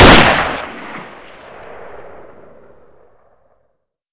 Index of /server/sound/weapons/cw_kk_hk416
fire.wav